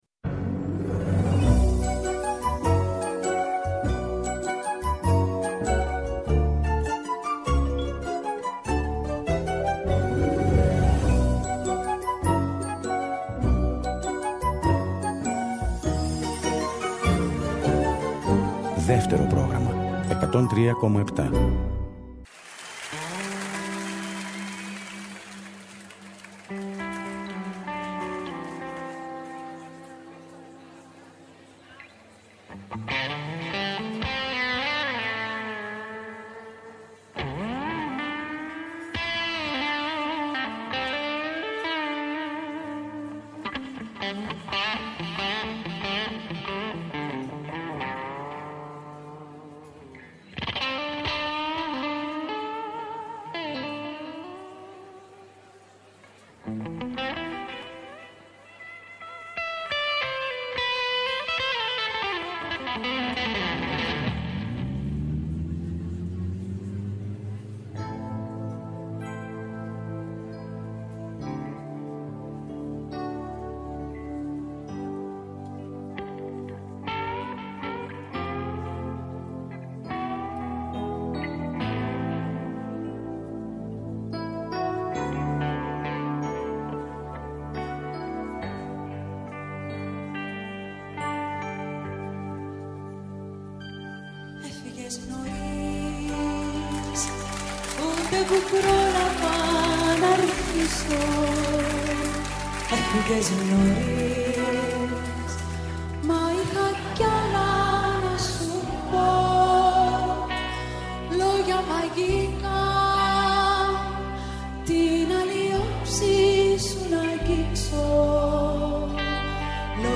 Τα τραγούδια της παρέας και πάντα το καινούργιο τραγούδι της ημέρας! Παλιά τραγούδια που κουβαλάνε μνήμες αλλά και νέα που πρόκειται να μας συντροφεύσουν.